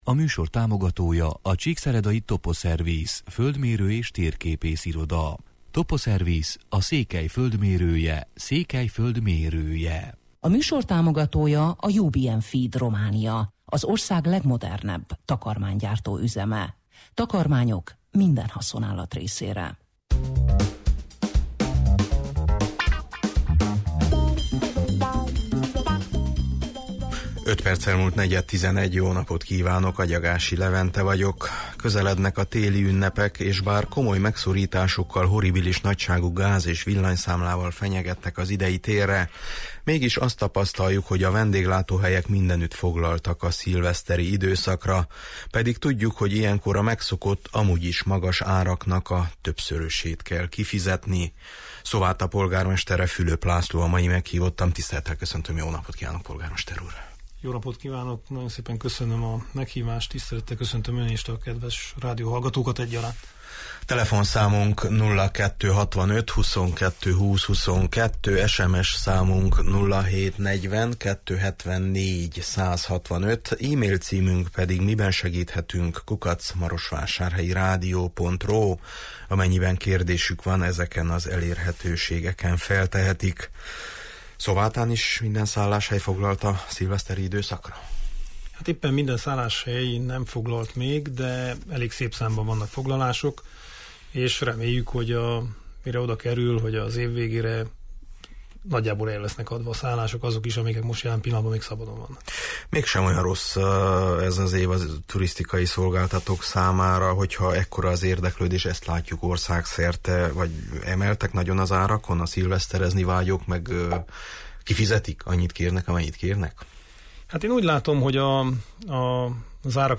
Szováta polgármestere, Fülöp László a mai meghívottam: